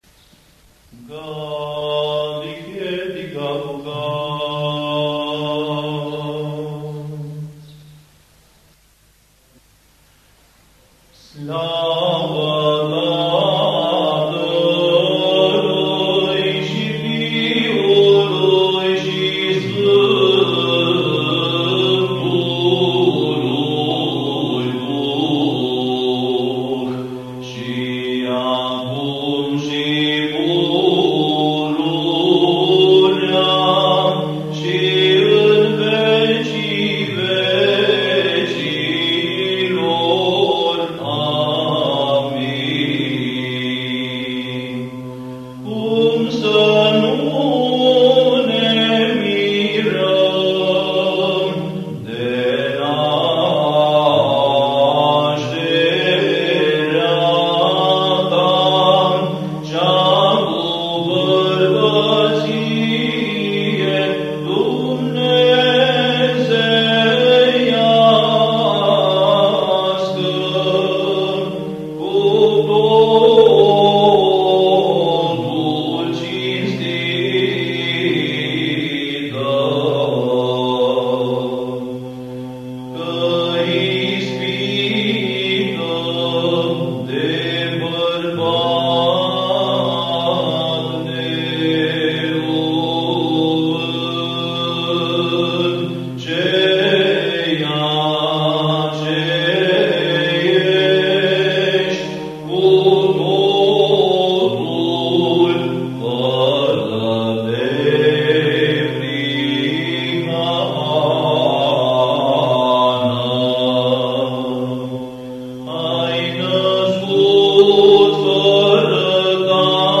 Index of /INVATAMANT/Facultate Teologie pastorala/Muzică bisericească și ritual/Dogmaticile
03. Dogmatica glasul 3.mp3